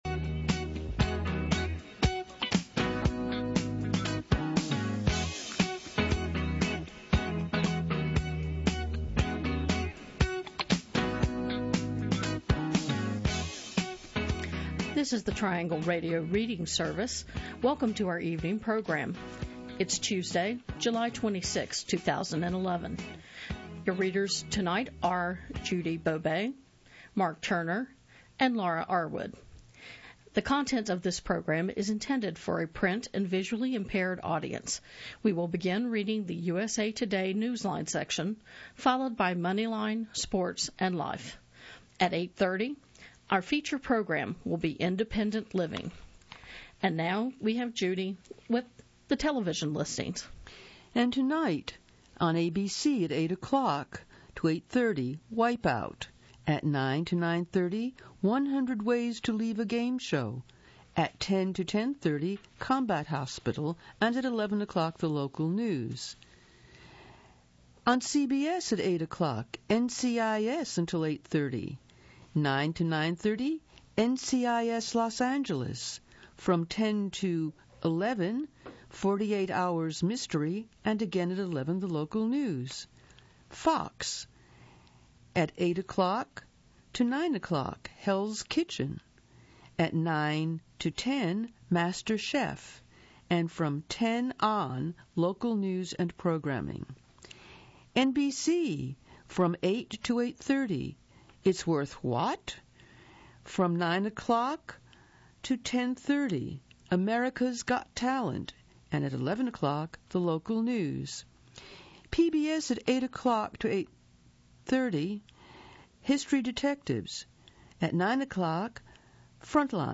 The Triangle Radio Reading Service is a radio service for the vision-impaired.
For instance, my voice sounds stressed, like I’m in a hurry.